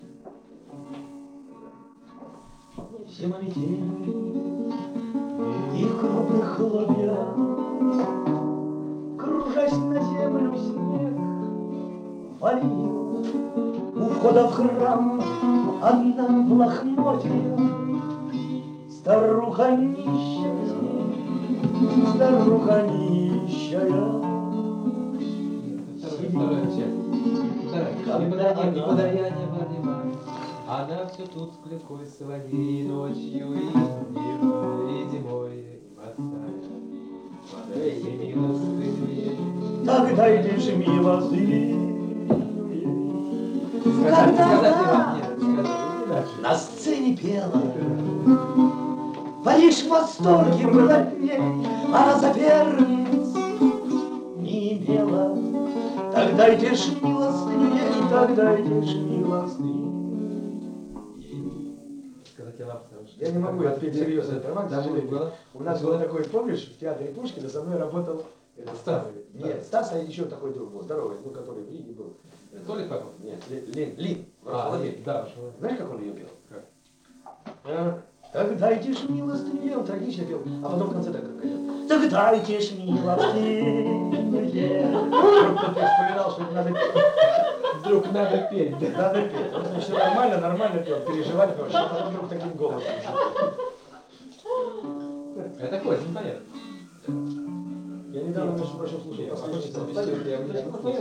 Переделкино На даче